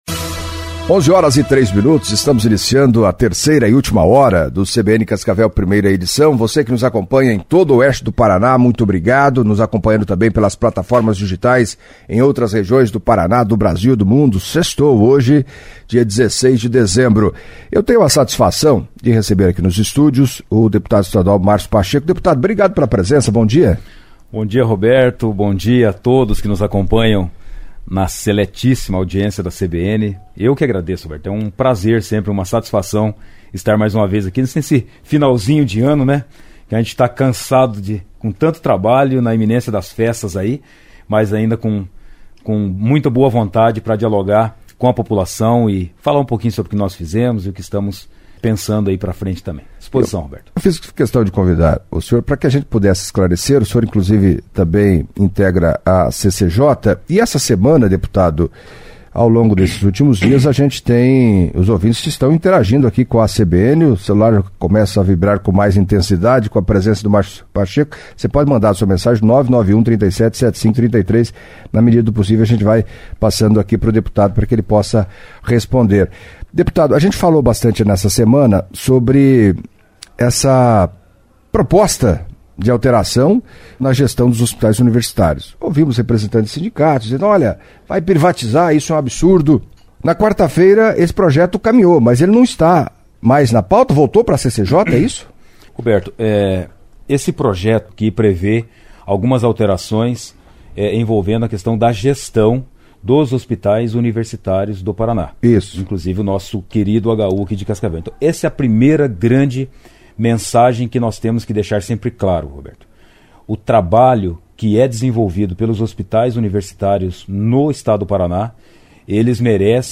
Em entrevista à CBN Cascavel nesta sexta-feria (16) o deputado estadual Márcio Pacheco, do Republicanos, falou de projetos de Lei que ainda estão em discussão na Assembleia Legislativa como, por exemplo, o que propõe mudança na gestão dos hospitais universitários, balanço dos trabalhos do ano, lamentou o fato de Bolsonaro não seguir como presidente em 2023, perspectivas gerais para os próximos anos e das articulações entre possíveis nomes para a sucessão de Paranhos na prefeitura de Cascavel em 2024.